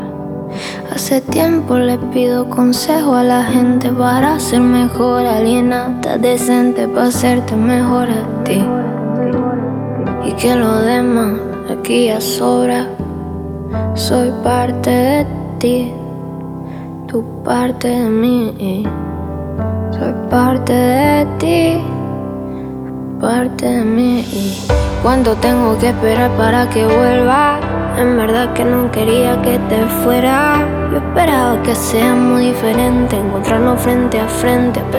Pop Latino, Latin